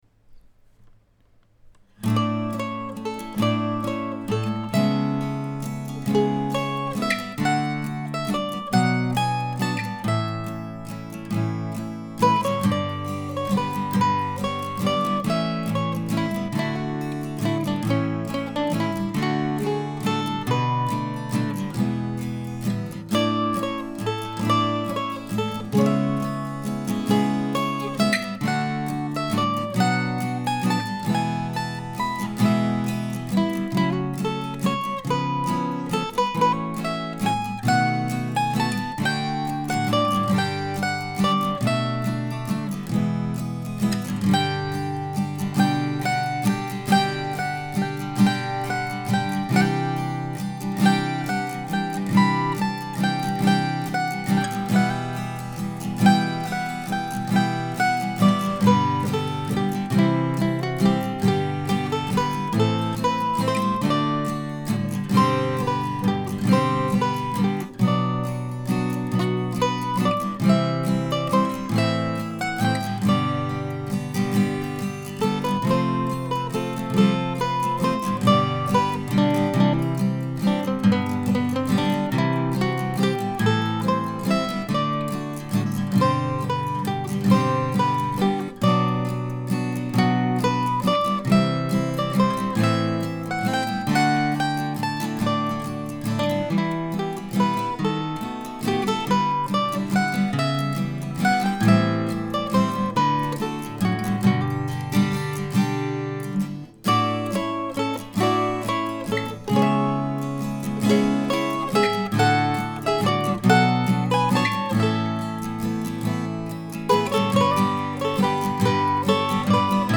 September Waltz September Waltz ( mp3 ) ( pdf ) A waltz today, written and recorded before some late week challenges.